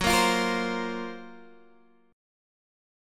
F#M#11 chord